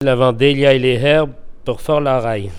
Mots Clé battage au fléau, perche ; Localisation Saint-Jean-de-Monts
Catégorie Locution